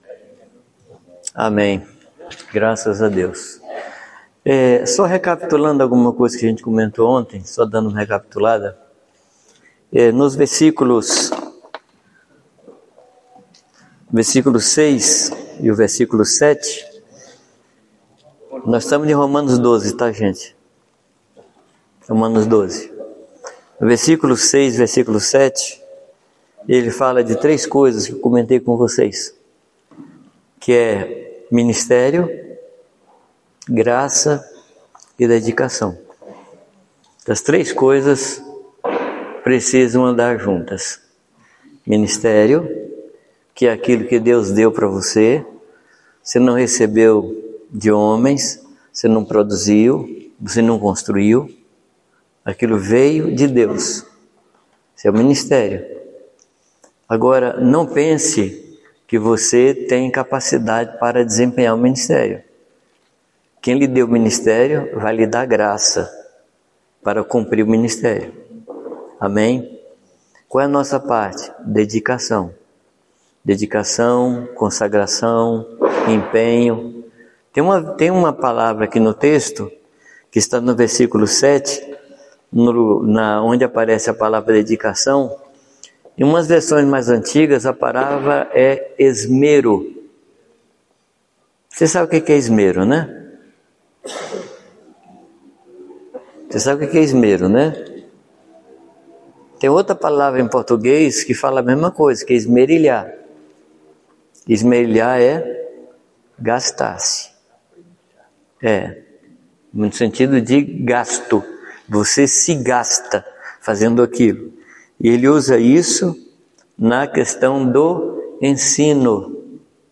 Encontro de Pastores